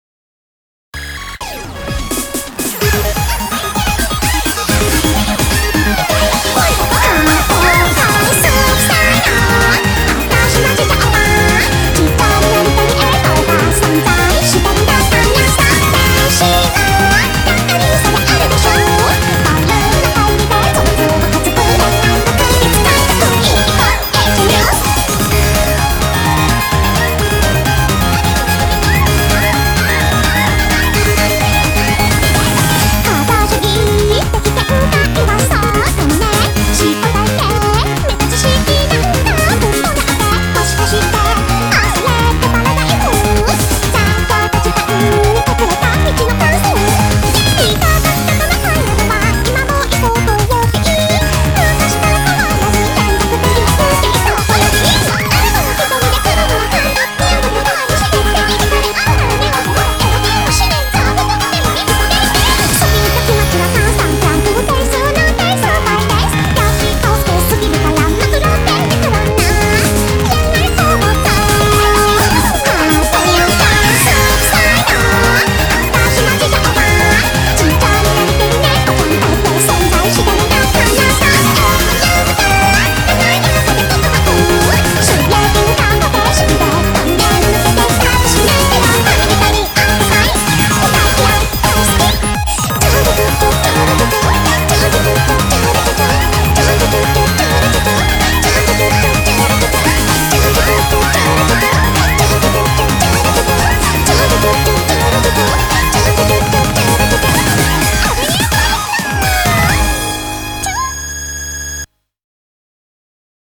BPM128-256
Audio QualityPerfect (Low Quality)